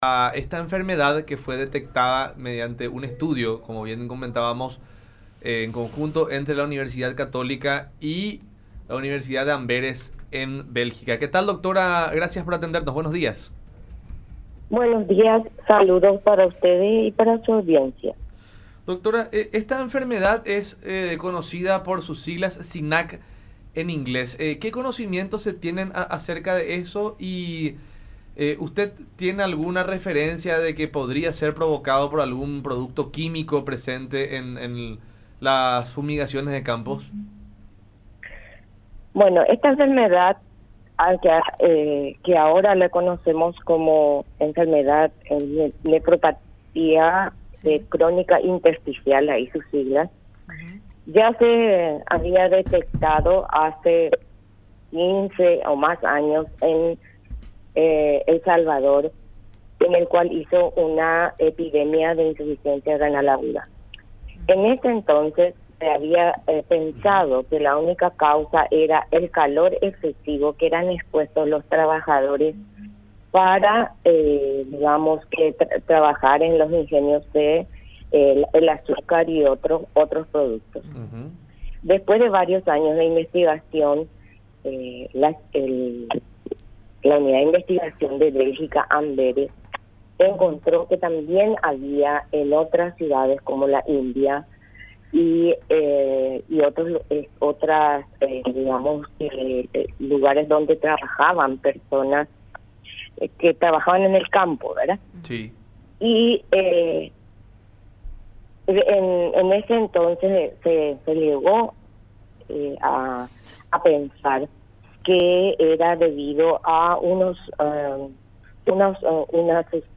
en diálogo con La Unión Hace La Fuerza por Unión TV y radio La Unión.